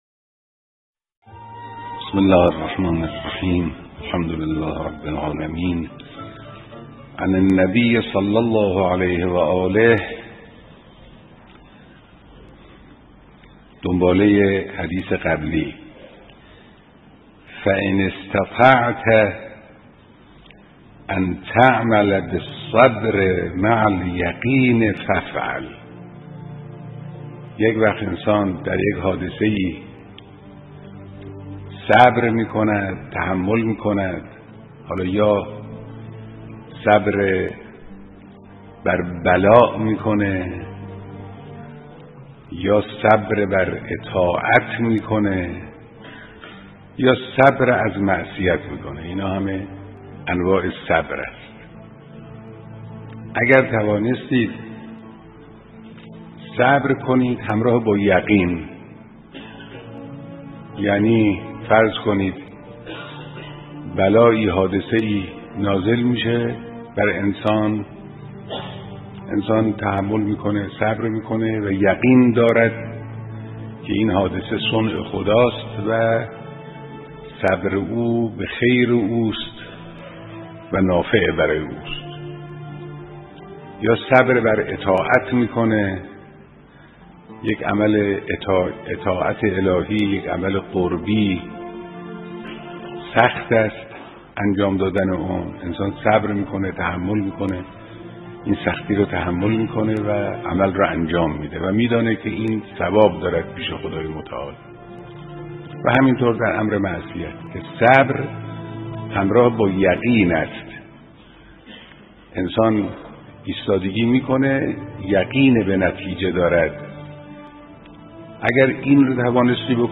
• صبر و یقین, کشایش و سختی, حدیث پیامبر اکرم, شرح حدیث, صبر و پیروزی, صوت بیانات رهبر انقلاب اسلامی